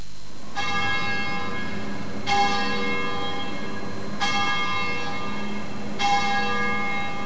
bells12.mp3